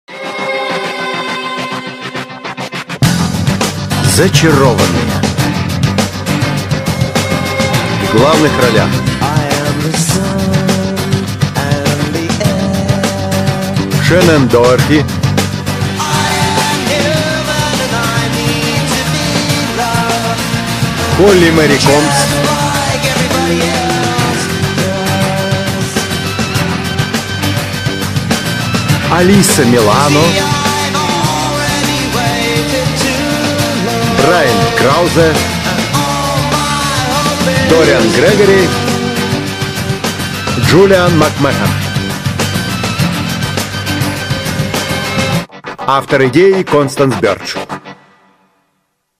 Мелодия титров в русском варианте